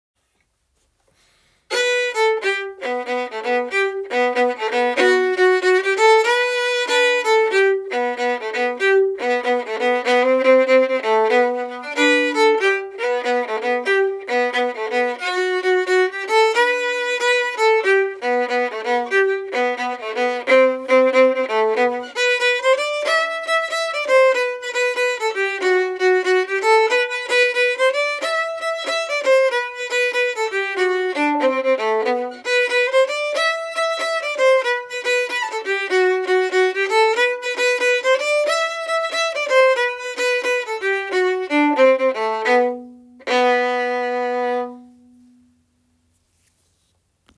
Hen’s Feet and Carrots is the melody to a Danish folk song
Hen’s Feet and Carrots harmony – 2024 version (audio mp3)Download